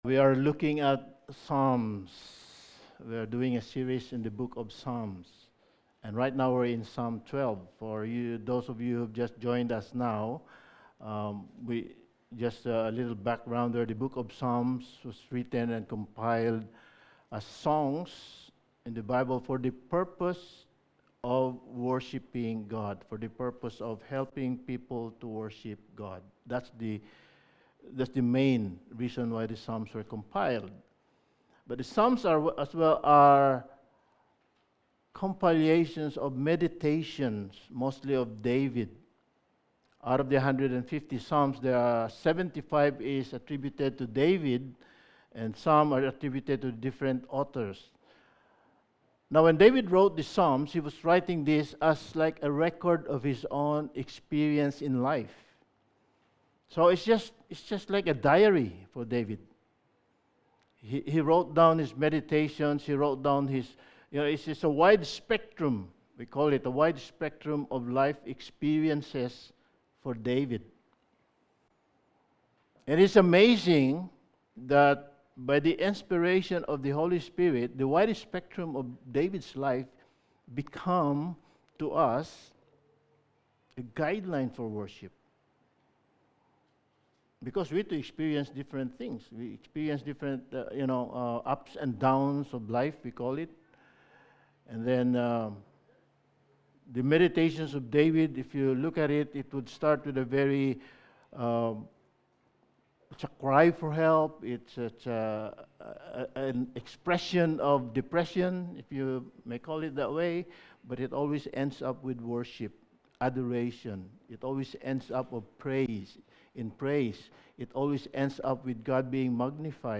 Sermon
Passage: Psalm 12:1-8 Service Type: Sunday Morning Sermon